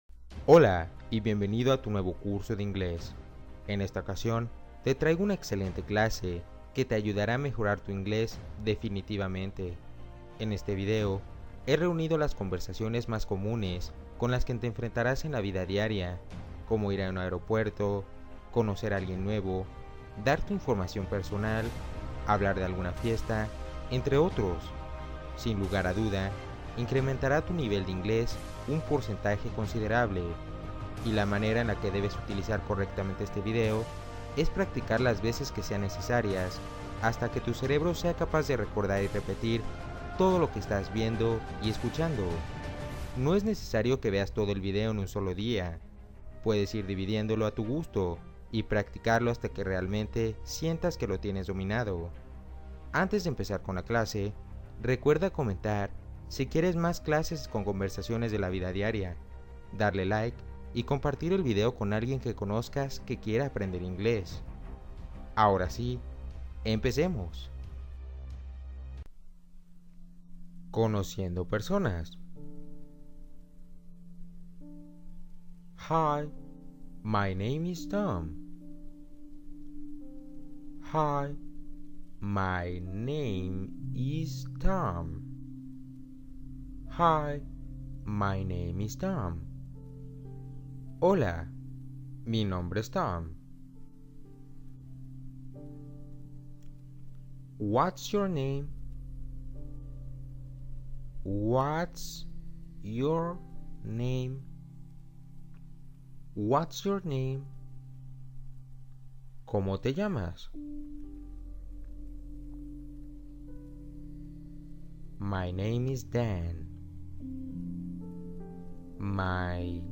Diálogo básico guiado para practicar inglés con apoyo en español